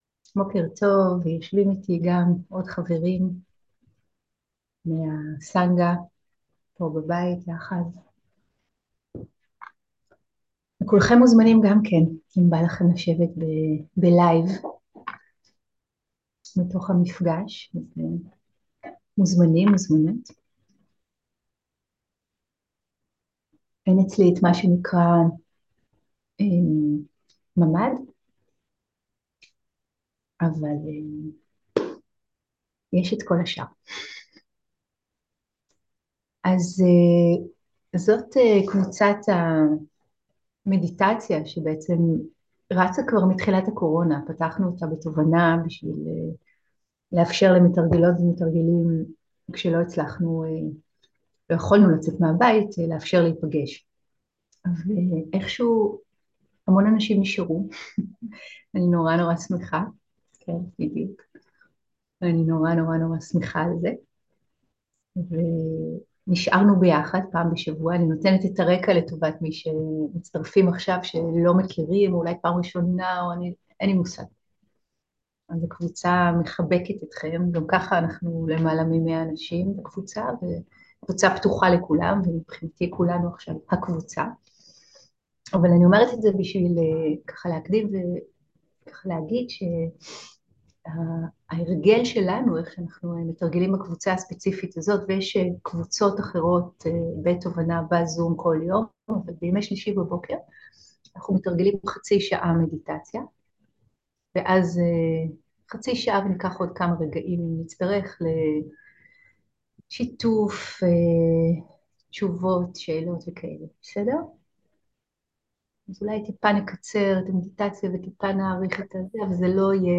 17.10.2023 - מרחב בטוח - להתקרקע, להתמשאב ולעשות - מדיטציה + שאלות ותשובות